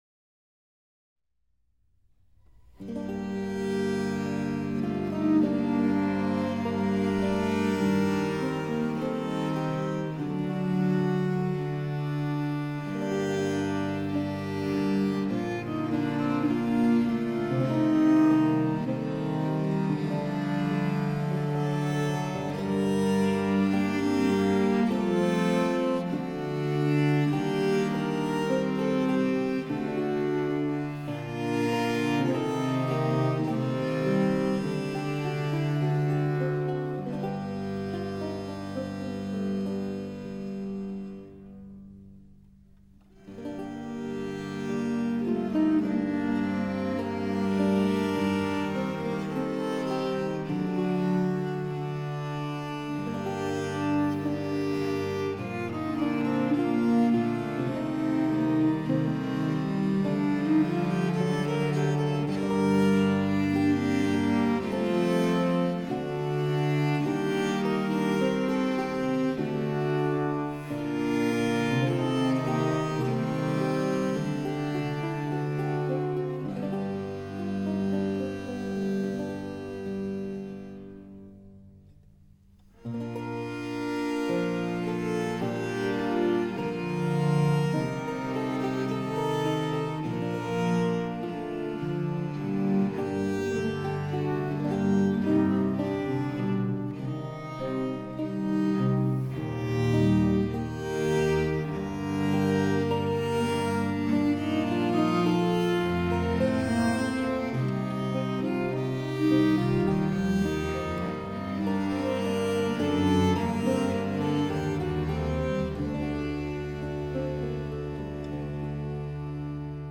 道兰最著名的器乐作品集为《泪，或为七首热情的帕凡舞曲所表现的七滴泪》（Lachrimae or Seaven Teares in Seaven Passionate Pavans），由21部完全和谐康索特所组成。它以七首热情的帕凡开头，这些帕凡舞曲基于一个由四个音符所组成的主题，每首帕凡都恰当的说明了"泪"这个词，由五把维奥尔琴和鲁特琴所演奏，非常庄严而响亮。